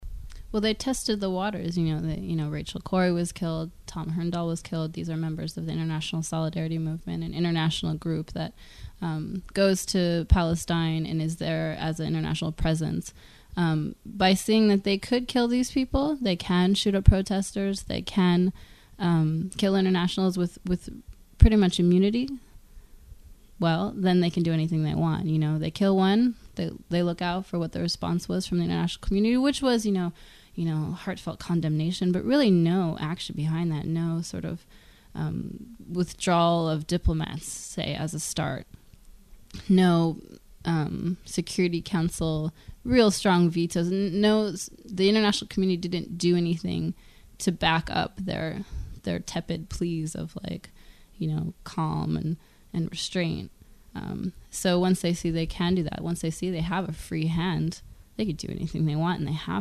Palestine Interview